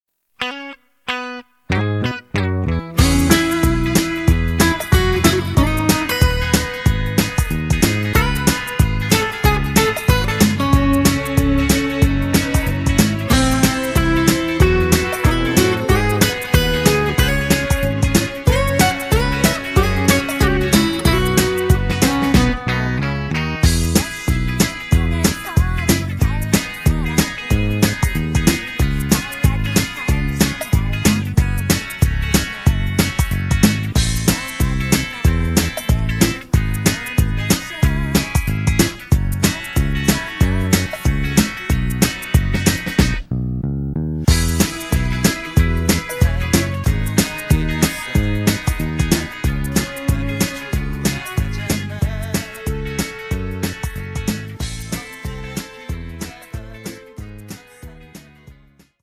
음정 원키 3:46
장르 가요 구분 Voice Cut